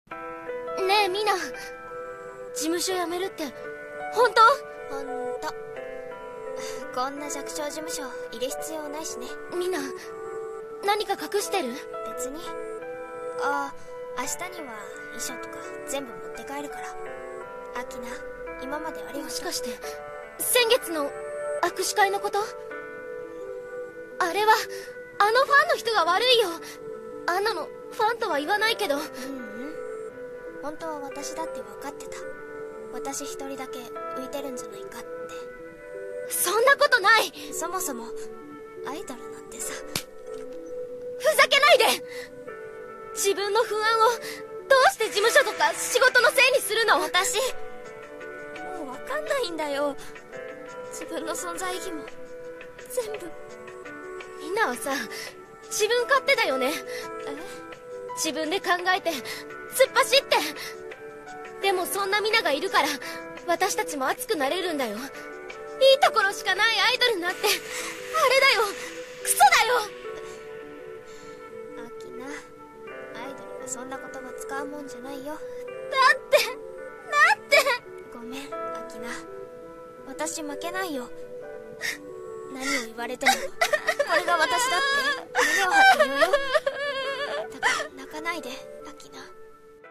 【コラボ声劇】追いかけた夢の果て